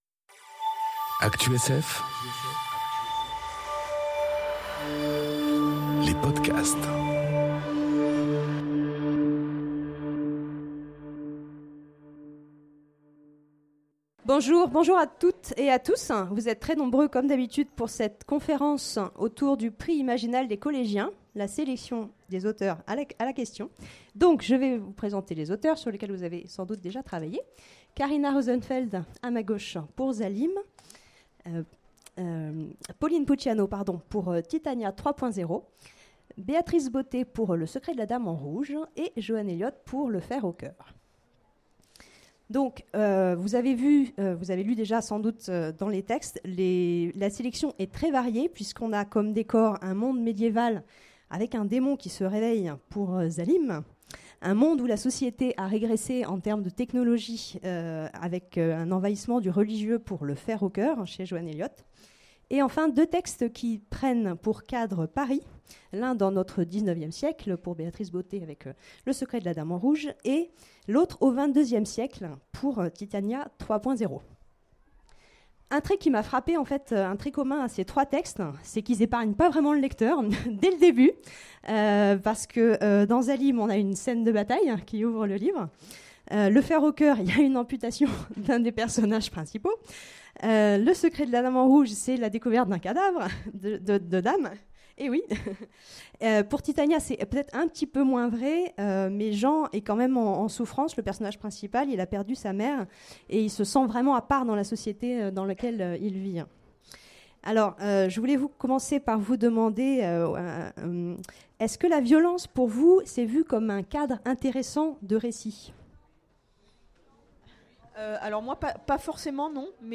Conférence Prix Imaginales des collégiens : la sélection 2018 à la question enregistrée aux Imaginales 2018